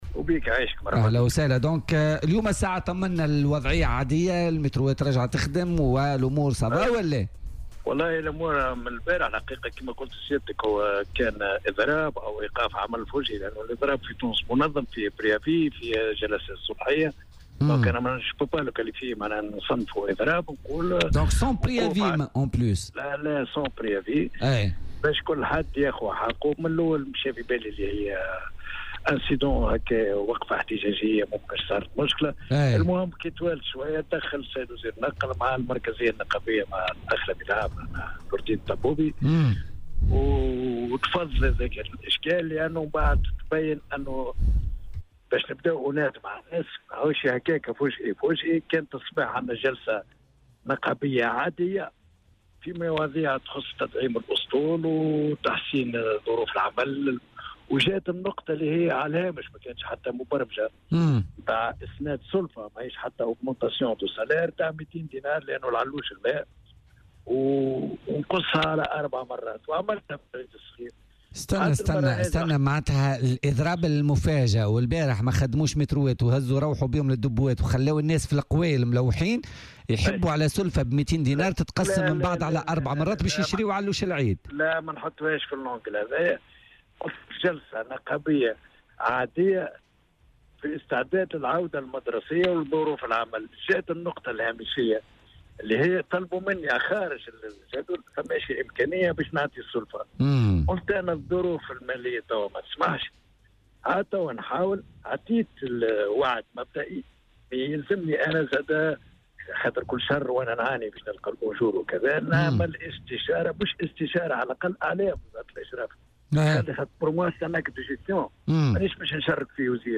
وأضاف في مداخلة له اليوم في برنامج "بوليتيكا" أنه تمت معاينة الأعوان الذين تخلفوا عن العمل وستتم مساءلتهم، مؤكدا أن إيقاف العمل كان بشكل فجئي ودون سابق إعلام.